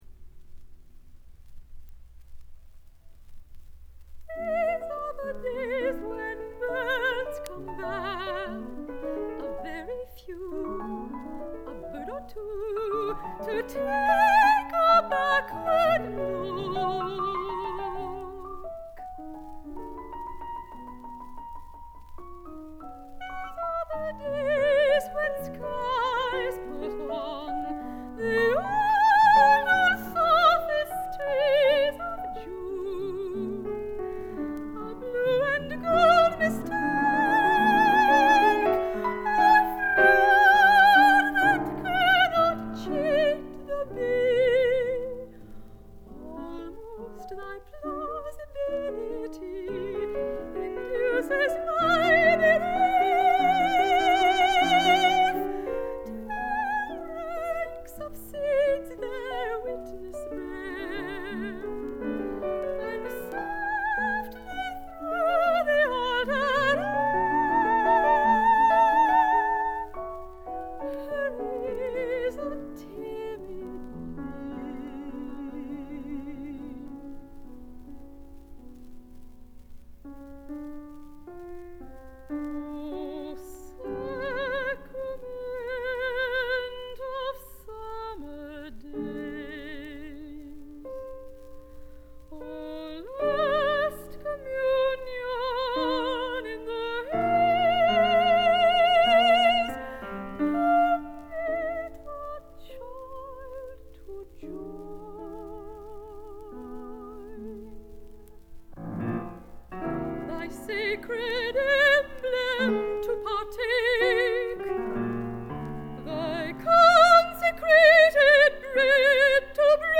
mezzo
piano